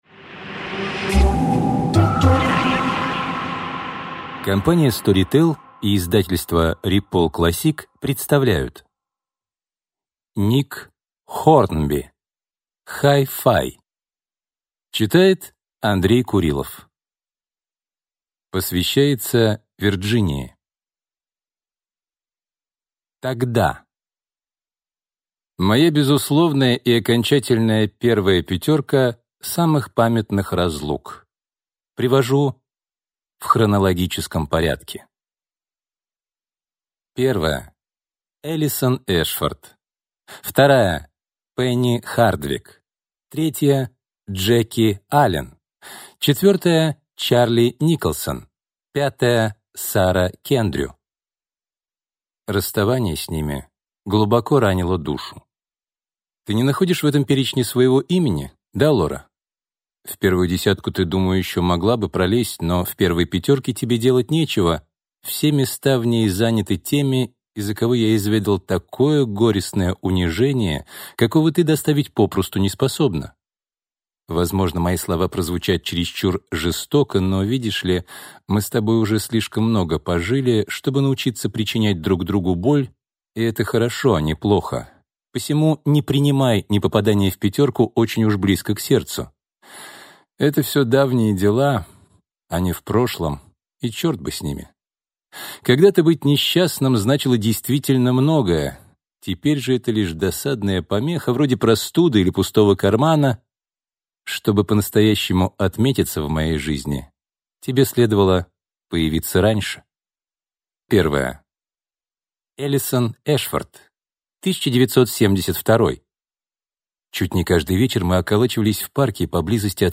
Аудиокнига Hi-Fi | Библиотека аудиокниг